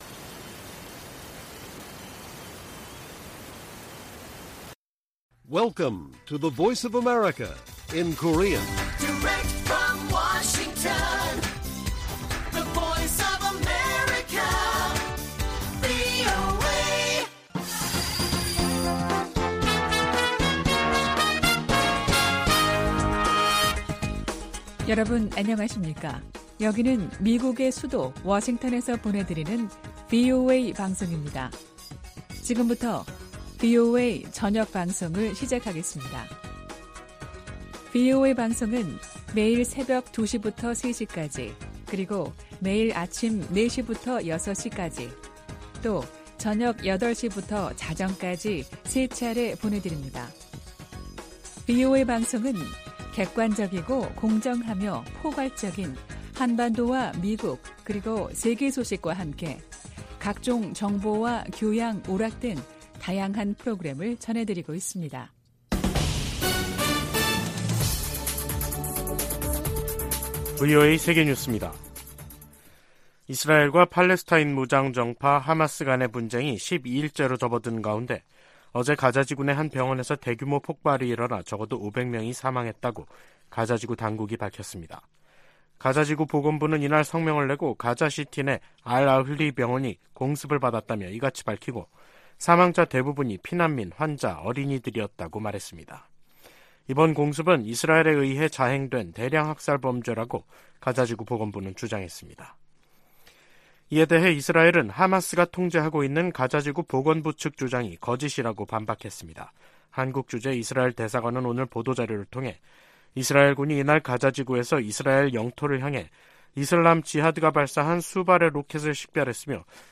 VOA 한국어 간판 뉴스 프로그램 '뉴스 투데이', 2023년 10월 18일 1부 방송입니다. 미 국무부가 러시아 외무장관 방북과 관련해 러시아가 첨단 군사기술을 북한에 넘길 것을 우려하고 있다고 밝혔습니다. 미 인도태평양사령관은 북한과 러시아 간 무기 거래 등 최근 움직임으로 역내 위험성이 커졌다고 지적했습니다. 팔레스타인 무장 정파 하마스가 가자지구에서 북한제 무기를 사용한다고 주한 이스라엘 대사가 VOA 인터뷰에서 말했습니다.